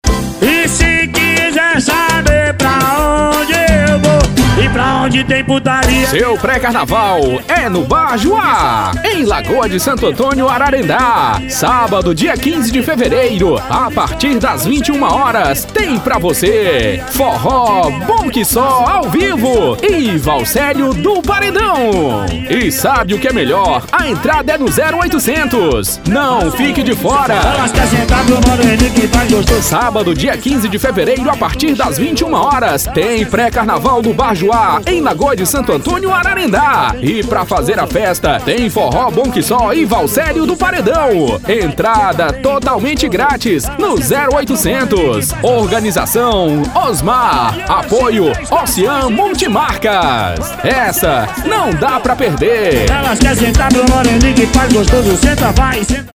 Demo Pré Carnaval Up-Festa:
Spot Comercial